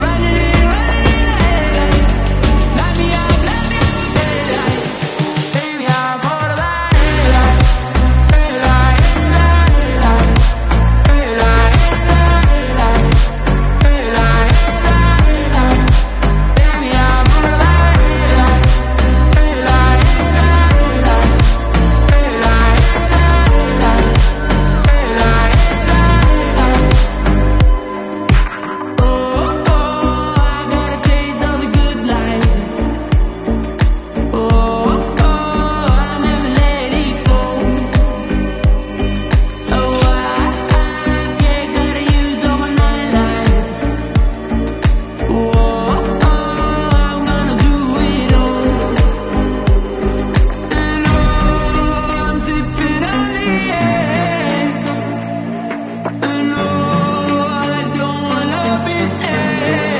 Tui 737 taking off from sound effects free download
Tui 737 taking off from EGNX / EMA